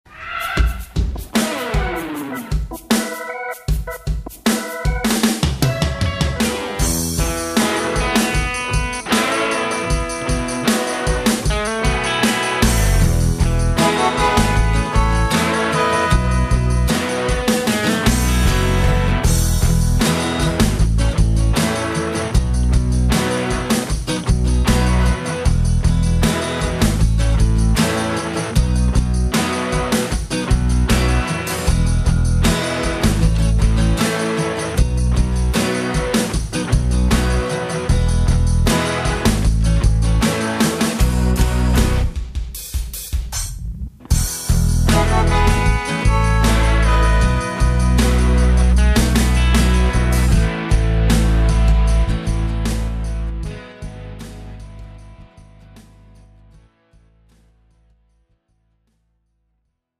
팝송